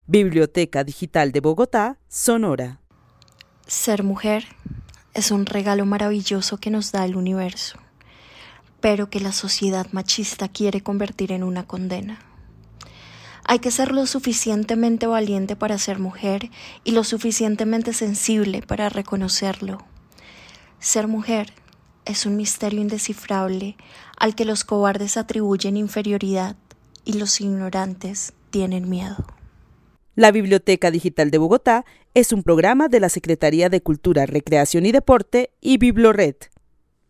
Narración oral sobre lo que significa ser mujer. La narradora considera que es un regalo maravilloso que el machismo quiere convertir en condena.
El testimonio fue recolectado en el marco del laboratorio de co-creación "Postales sonoras: mujeres escuchando mujeres" de la línea Cultura Digital e Innovación de la Red Distrital de Bibliotecas Públicas de Bogotá - BibloRed.